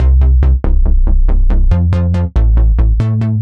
Index of /musicradar/french-house-chillout-samples/140bpm/Instruments
FHC_NippaBass_140-C.wav